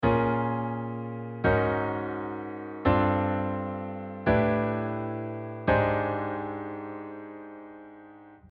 In this case I am modulating from Db major to Eb major tonality – Ab Lydian